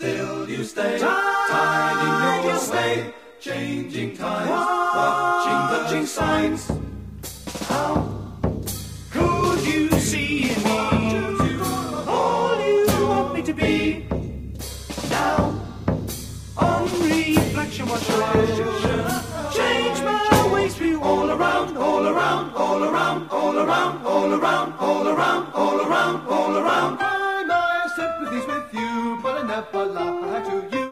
This audio sampled at 22.05KHz.